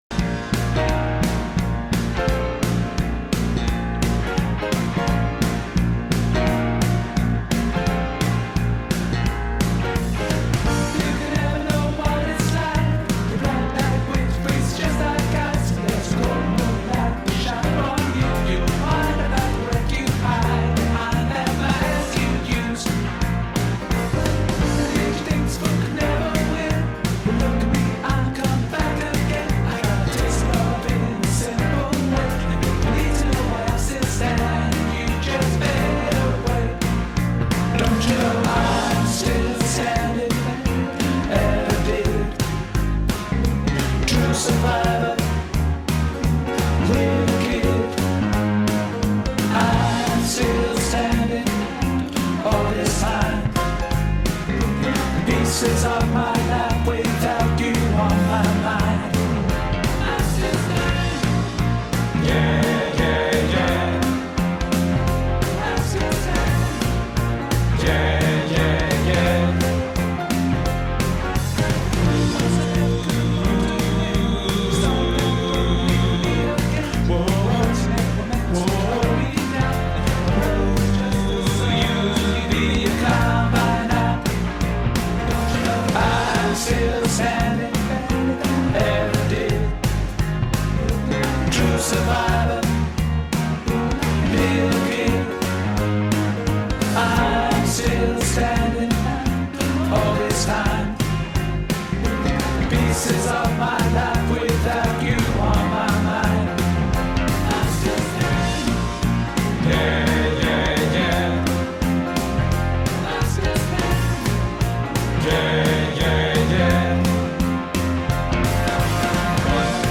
I’m Still Standing Bass Backing Track | Ipswich Hospital Community Choir